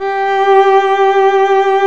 Index of /90_sSampleCDs/Roland - String Master Series/STR_Violin 1-3vb/STR_Vln1 _ marc